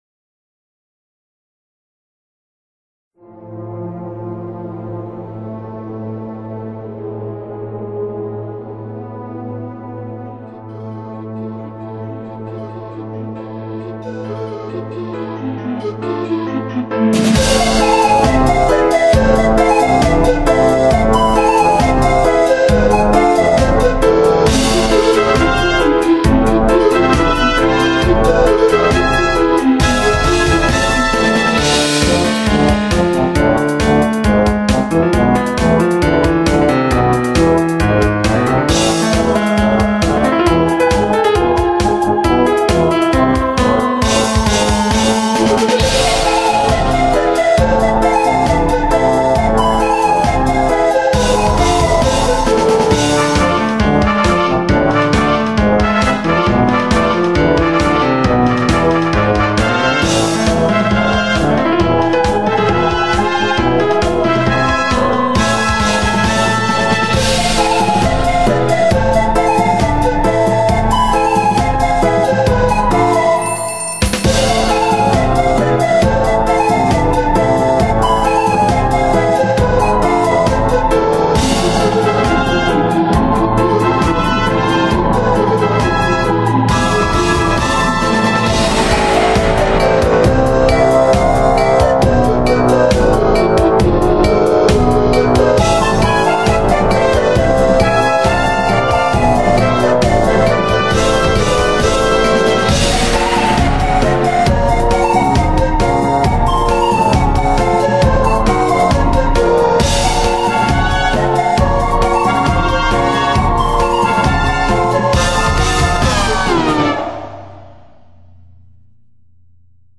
The Carnival Master (Original creepy music
I was also channeling some Danny Elfman here.